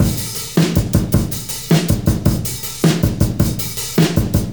• 106 Bpm Drum Loop A# Key.wav
Free drum beat - kick tuned to the A# note. Loudest frequency: 3207Hz
106-bpm-drum-loop-a-sharp-key-PcZ.wav